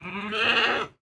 Minecraft Version Minecraft Version snapshot Latest Release | Latest Snapshot snapshot / assets / minecraft / sounds / mob / goat / pre_ram2.ogg Compare With Compare With Latest Release | Latest Snapshot